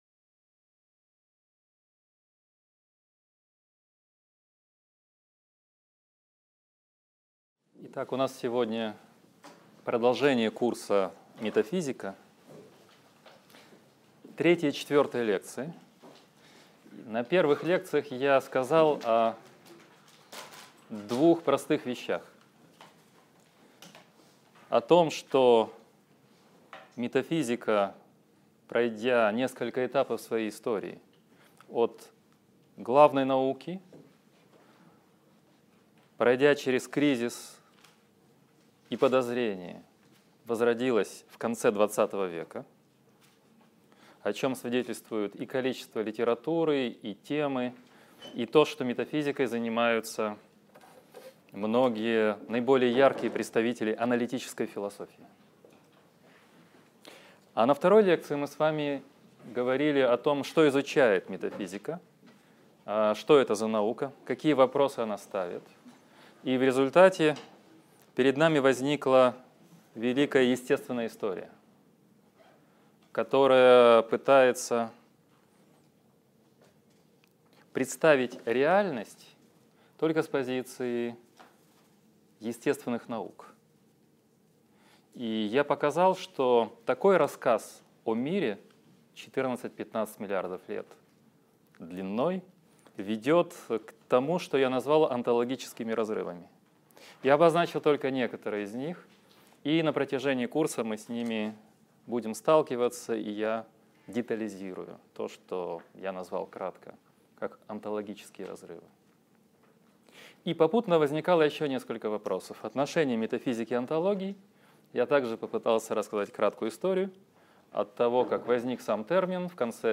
Аудиокнига Лекция 3. Постмодернизм против метафизики | Библиотека аудиокниг